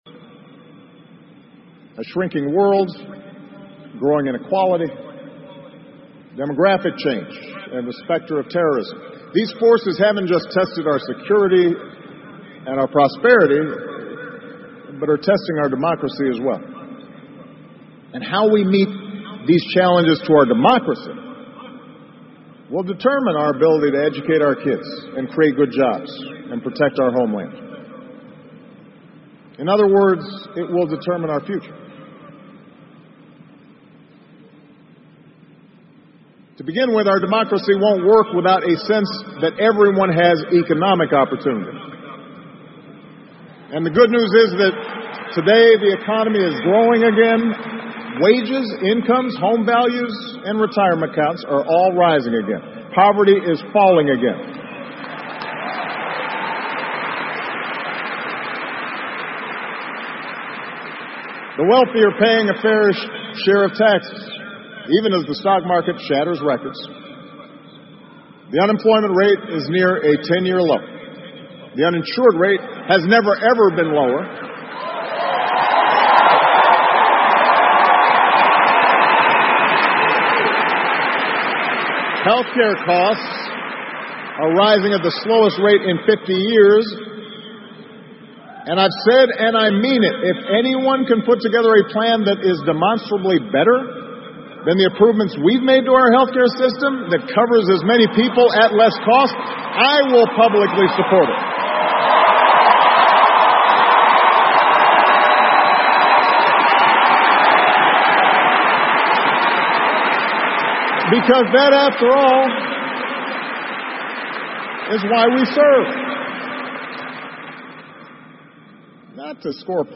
奥巴马每周电视讲话：美国总统奥巴马告别演讲(6) 听力文件下载—在线英语听力室